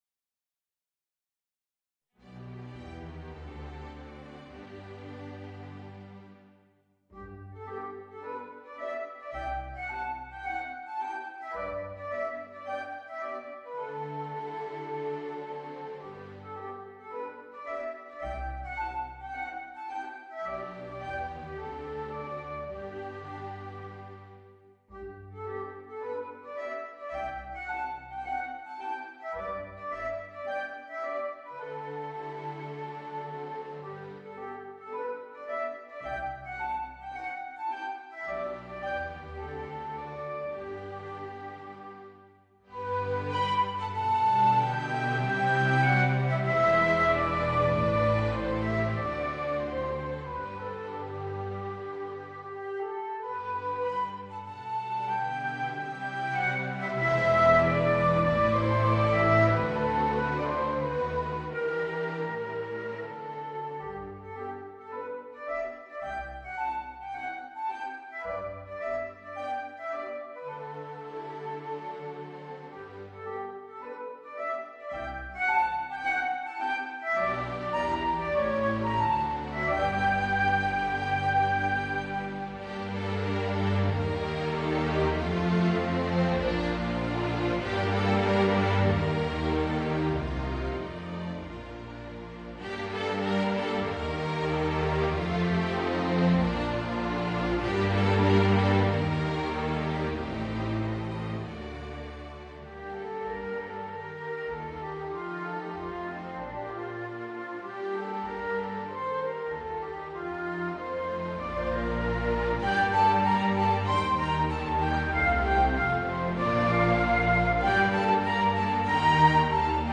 Voicing: Violoncello and String Orchestra